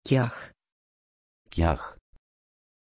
Pronunciation of Kiah
pronunciation-kiah-pl.mp3